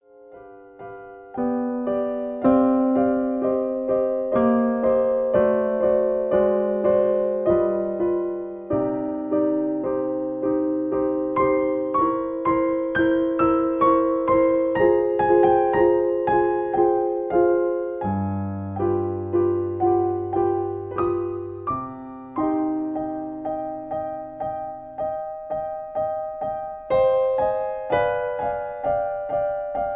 In C. Piano Accompaniment